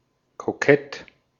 Ääntäminen
IPA : /kɔɪ/